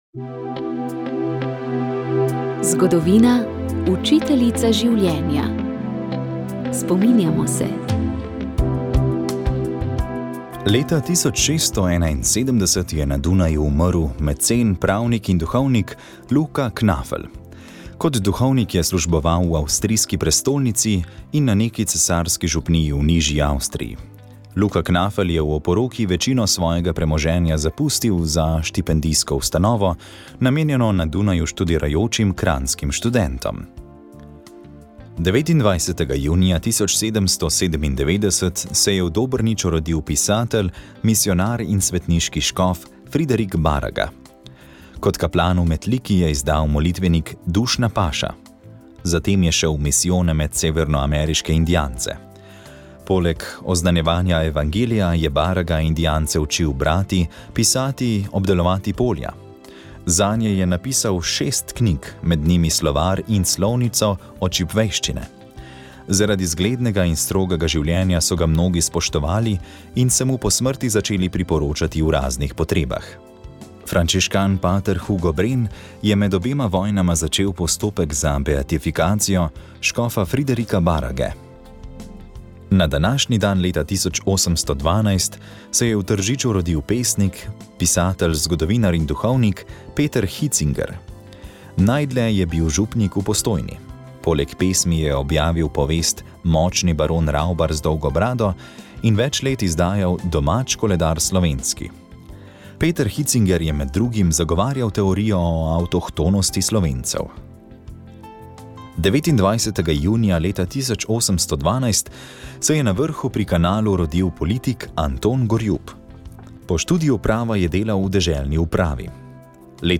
Dan pred slovenskim kulturnim praznikom bo Naš gost letošnji Prešernov nagrajenec za življenjsko delo arhitekt, industrijski oblikovalec in profesor Saša Mächtig, avtor legendarnega rdečega kioska K67. Z njim se bomo pogovarjali o njegovem življenju in delu, o družinski zgodovini pa tudi o preživljanju praznika kulture, ki je za nas Slovence poseben dan.